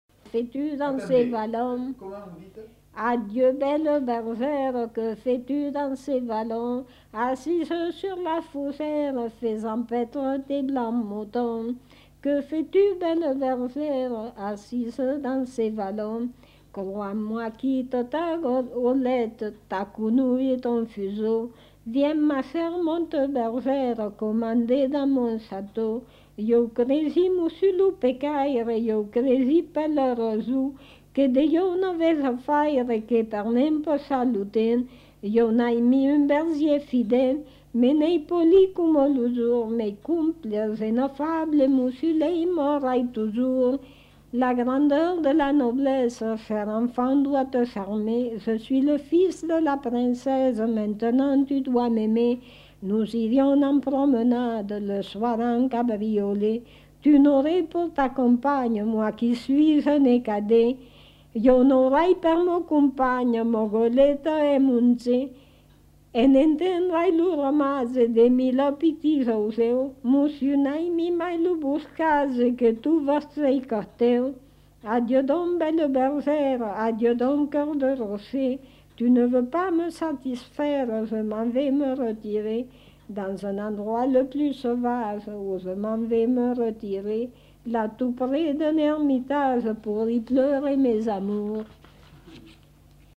Aire culturelle : Périgord
Lieu : La Chapelle-Aubareil
Genre : chant
Effectif : 1
Type de voix : voix de femme
Production du son : chanté